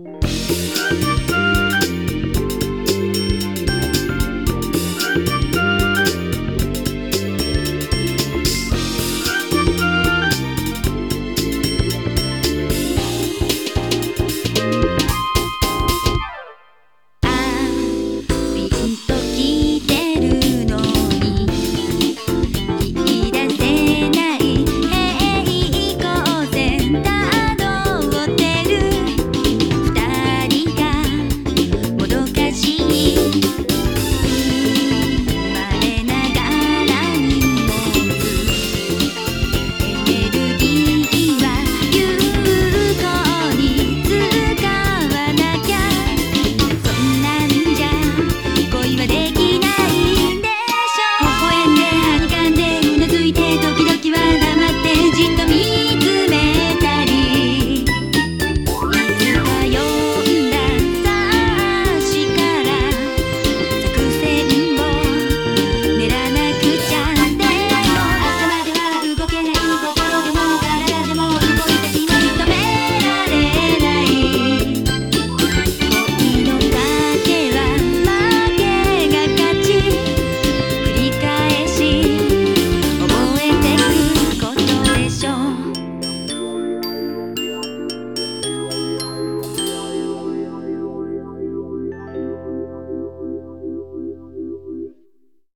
BPM96-113
Audio QualityPerfect (Low Quality)